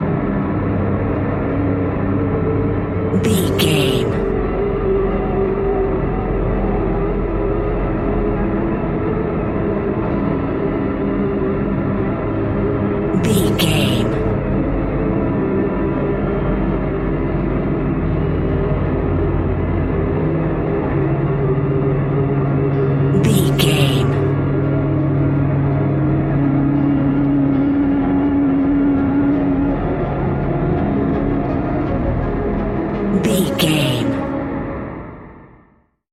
Ionian/Major
C♭
dark ambient
EBM
synths